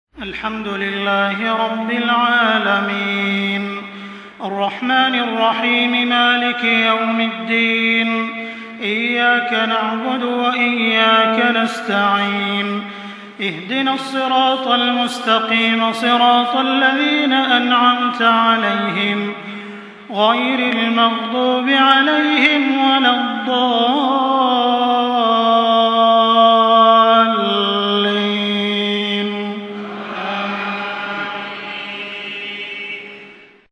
Surah আল-ফাতিহা MP3 by Makkah Taraweeh 1424 in Hafs An Asim narration.
Murattal Hafs An Asim